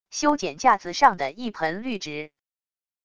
修剪架子上的一盆绿植wav音频